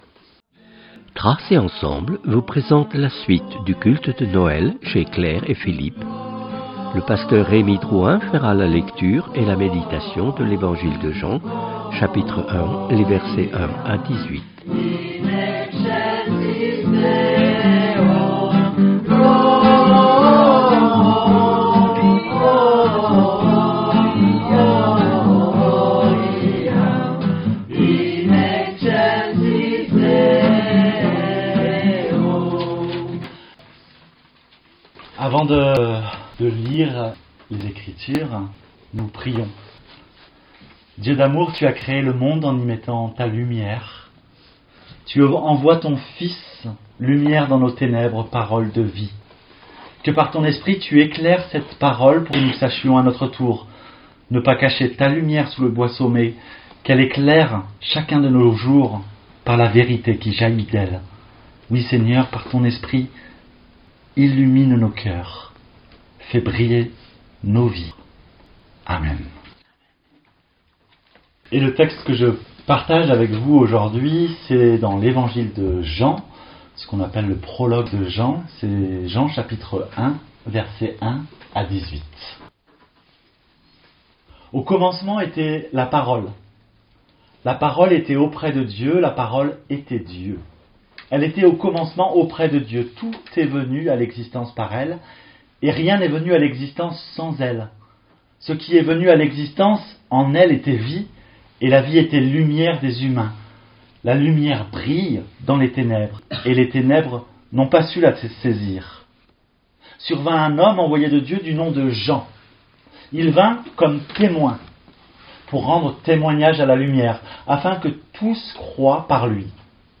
Culte